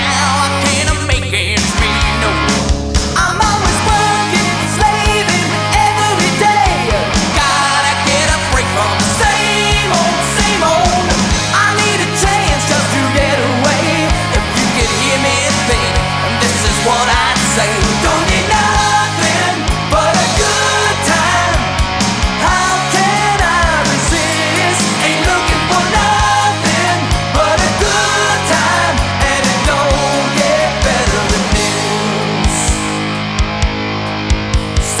Hard Rock
Жанр: Рок